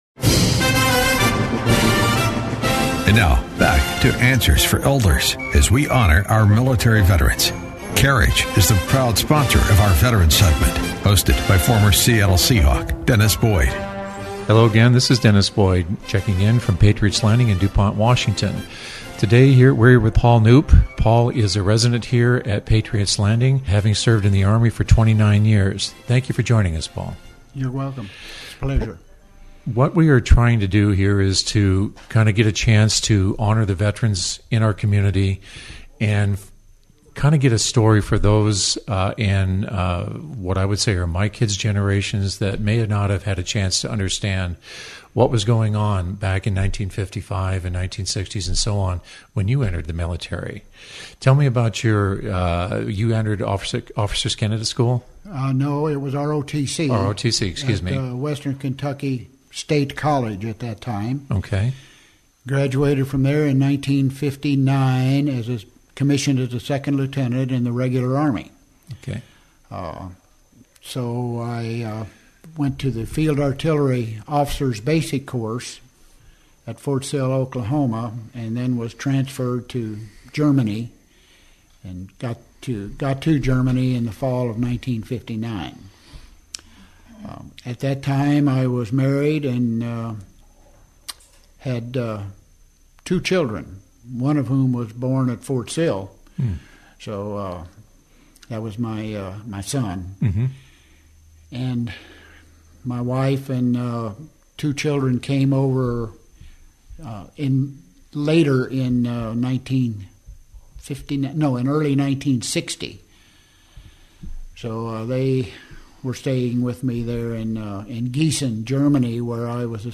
Veterans Interview